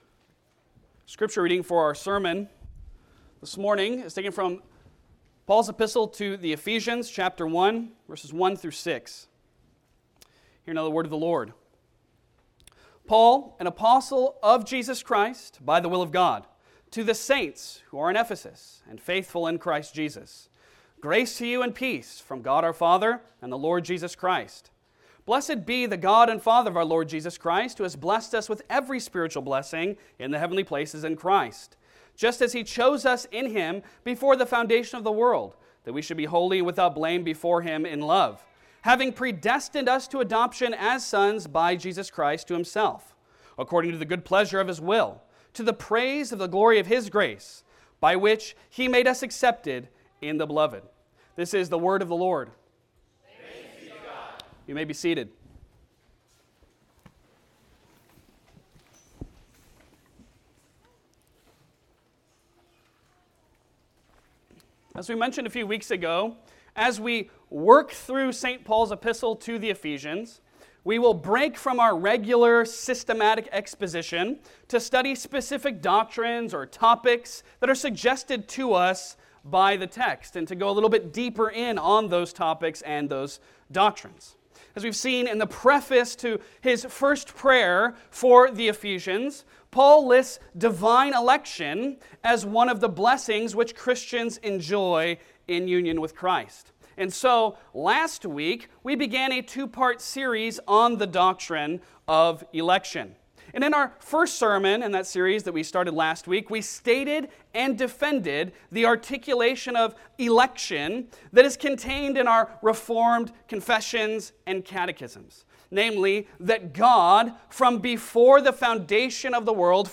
Passage: Ephesians 1:1-6 Service Type: Sunday Sermon